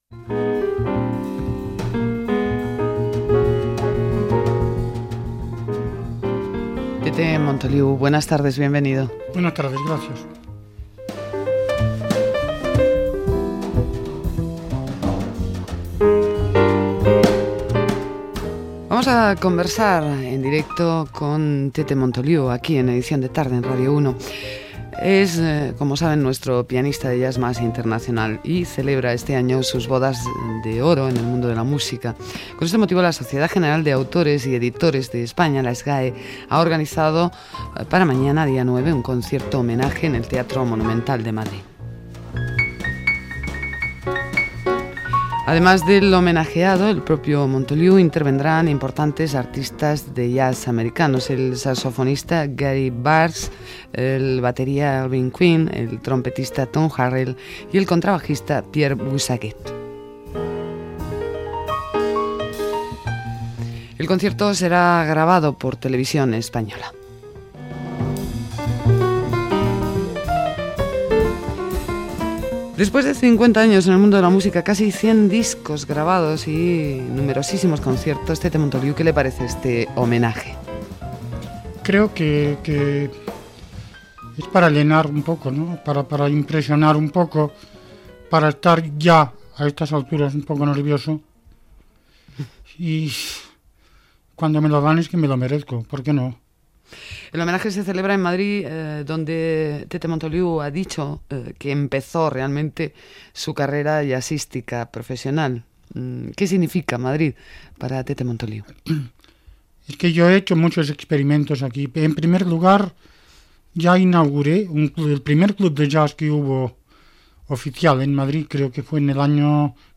Entrevista al pianista Tete Montoliu amb motiu de l'homenatge que li farà a l'endemà la Sociedad Española General de Autores (SGAE), a Madrid